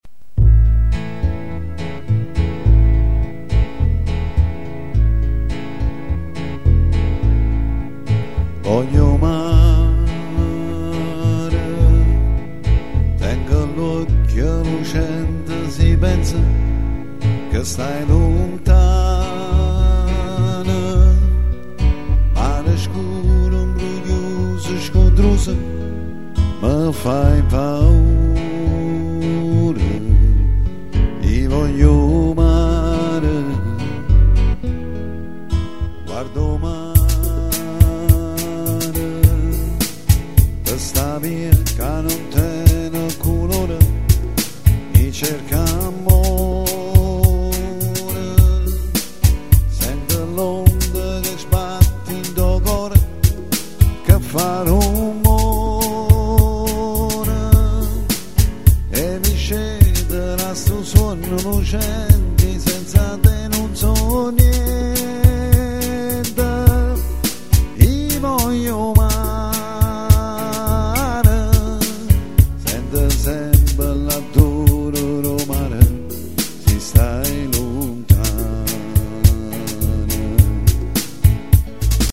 Farsa in Musica  per bambini
Versione Napoletana